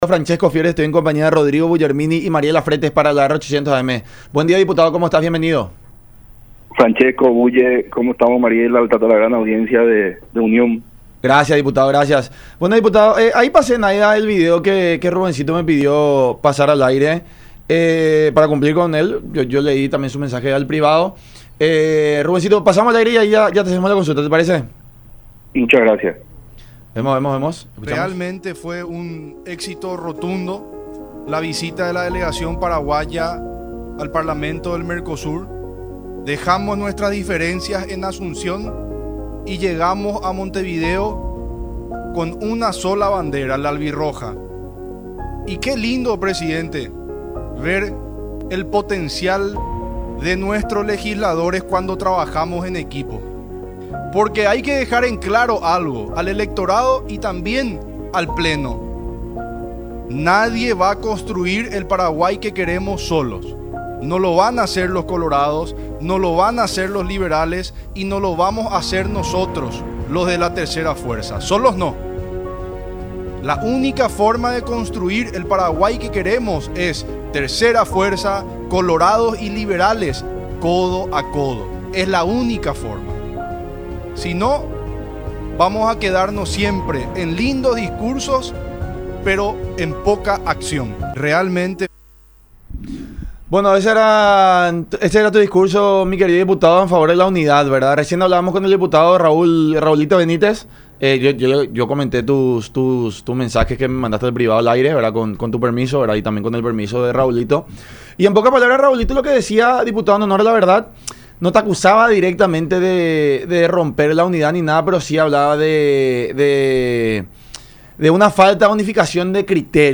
“Tenemos que ser autocríticos y pulir nuestros errores. Todos tenemos perfiles distintos, aceptar esas diferencias y llegar unidos al 2028” , declaró Rubén Rubín en el programa “La Mañana De Unión” por Unión TV y radio La Unión.